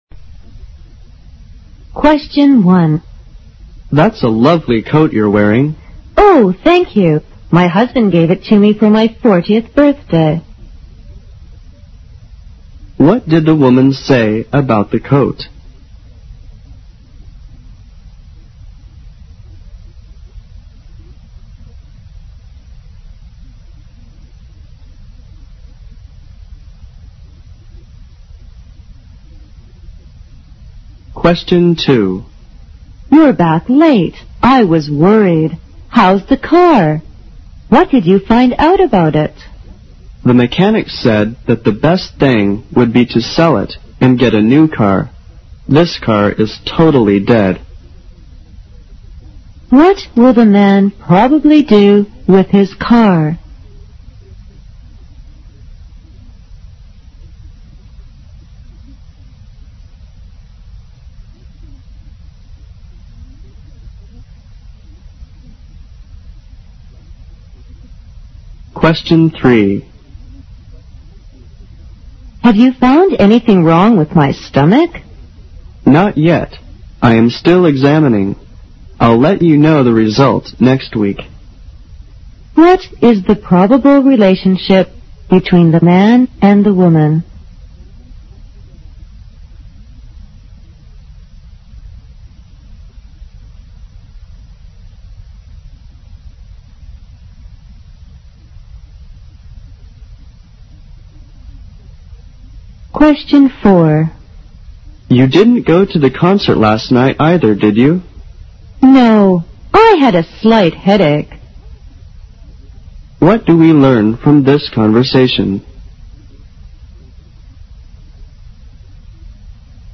【CET12-24备战】四级听力 第三期 Short Conversation 听力文件下载—在线英语听力室